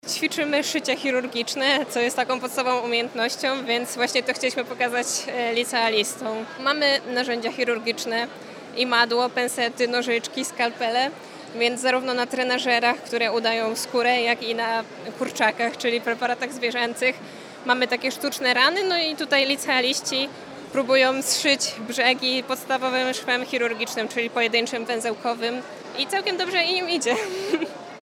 Dni otwarte Politechniki Wrocławskiej